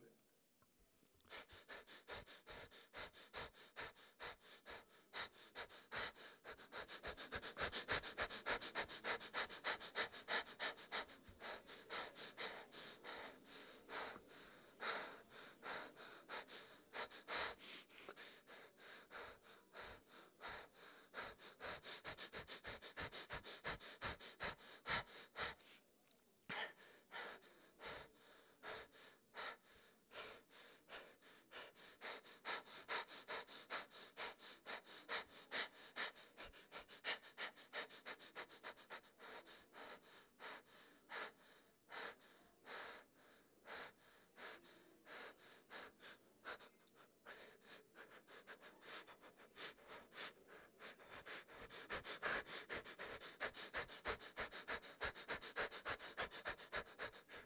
搅拌器
描述：Varias tomasderespiración。 Grabado con grabadora zoomH4n
Tag: 搅拌 通过 呼吸 空气